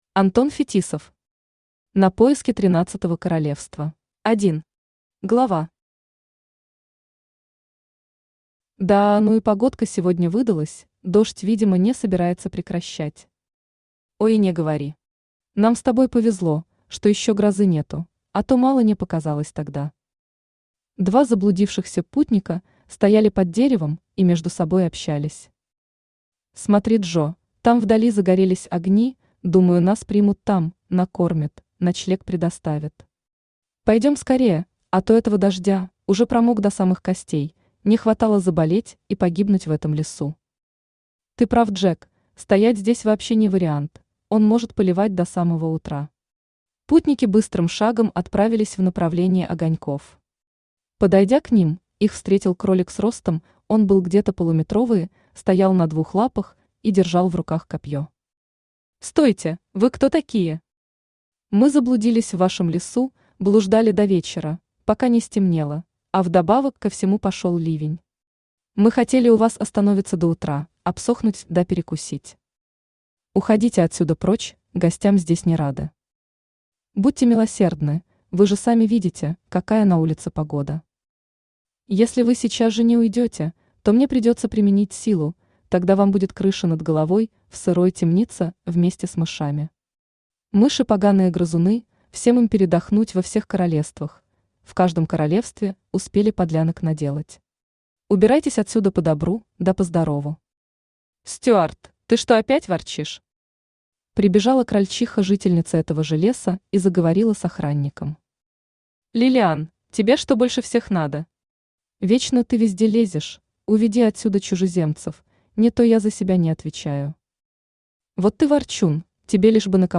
Aудиокнига На поиски тринадцатого королевства Автор Антон Евгеньевич Фетисов Читает аудиокнигу Авточтец ЛитРес.